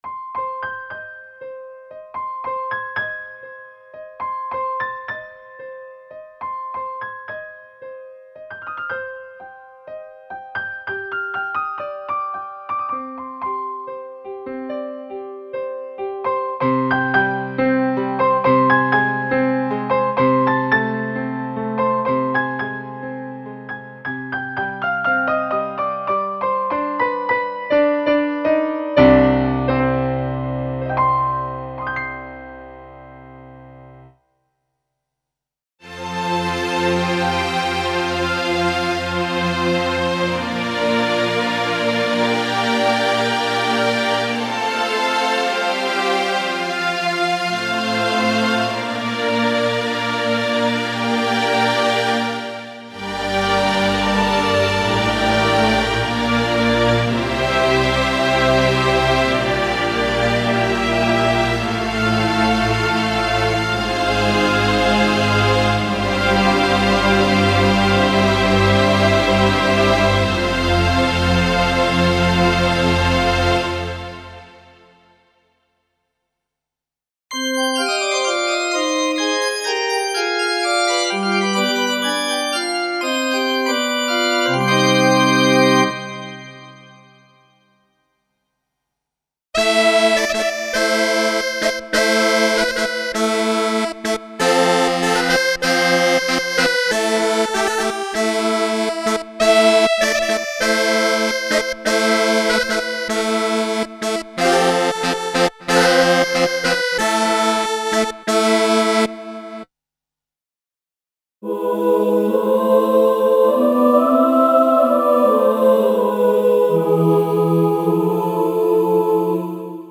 Comprehensive collection of classical instruments (acoustic pianos, guitars, pipe organs, strings, flutes, trumpets, saxophones, brass sections) and voices.
Info: All original K:Works sound programs use internal Kurzweil K2500 ROM samples exclusively, there are no external samples used.
Note: This soundware collection is designed for Kurzweil K2500/K2500R synthesizers without optional ROM boards installed.